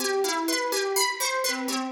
Index of /musicradar/shimmer-and-sparkle-samples/125bpm
SaS_Arp01_125-C.wav